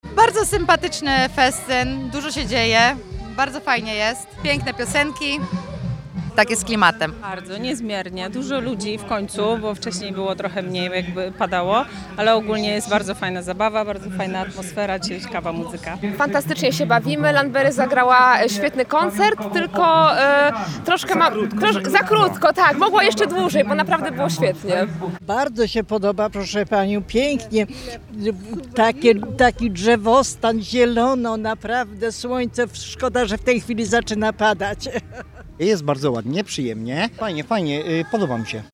– Bardzo fajne wydarzenie, dużo atrakcji i super muzyka – mówi jedna z uczestniczek wydarzenia.